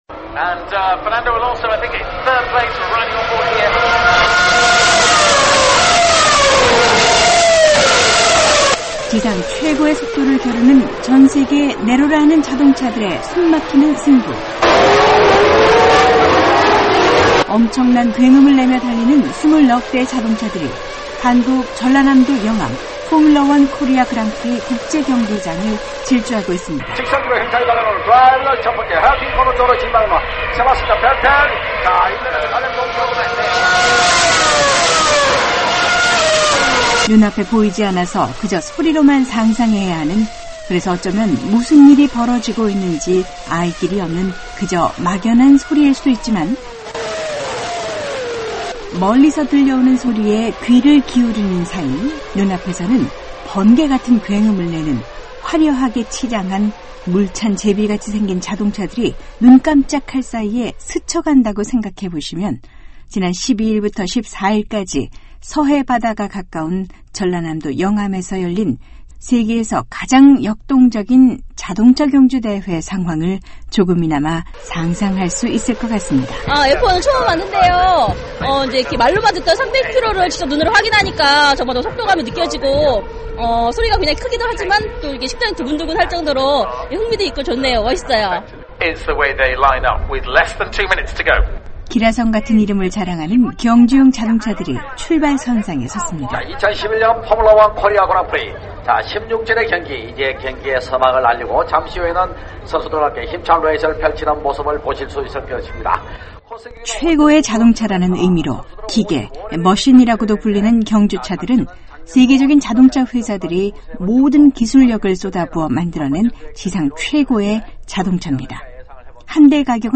안녕하세요. 서울입니다 오늘은 속도와 굉음에 환호하는 자동차 경주대회 현장으로 안내합니다.